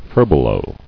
[fur·be·low]